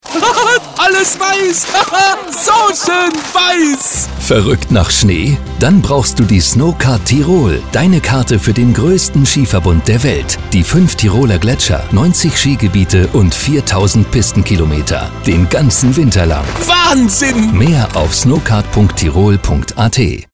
dunkel, sonor, souverän, markant, sehr variabel
Mittel plus (35-65)
Commercial (Werbung)